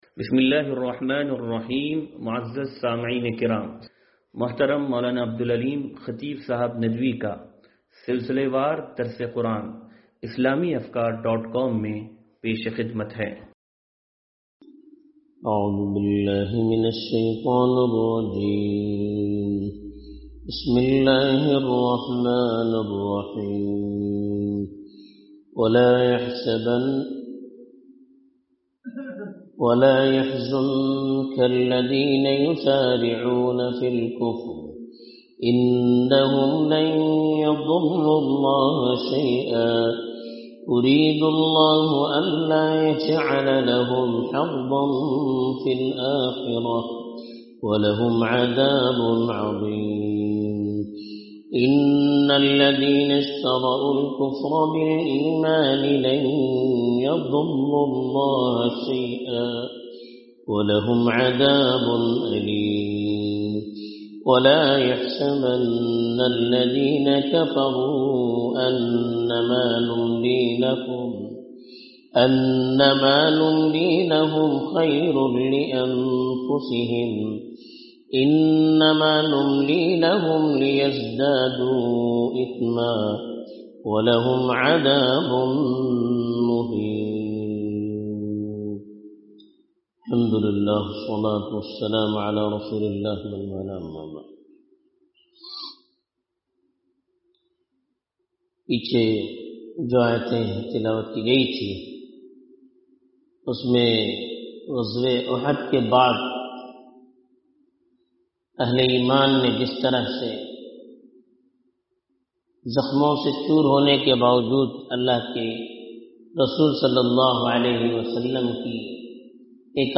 درس قرآن نمبر 0305